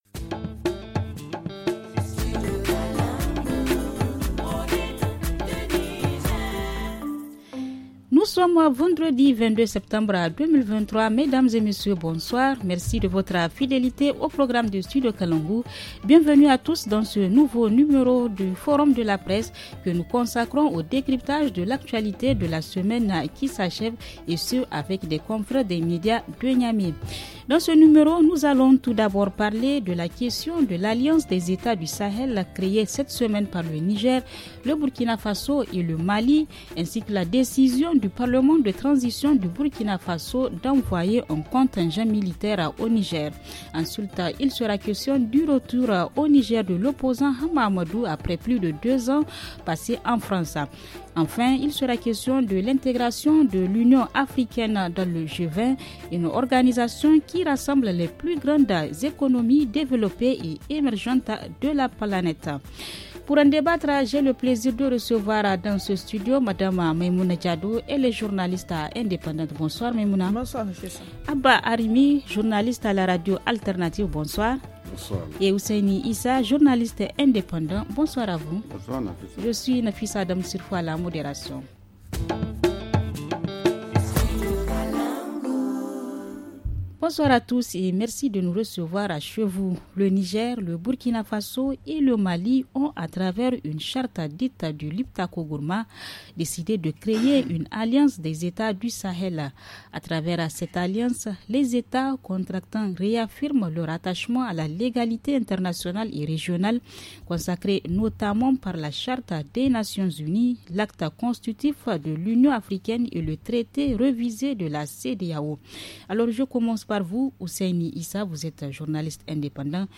Forum de la presse du 22 Septembre 2023 - Studio Kalangou - Au rythme du Niger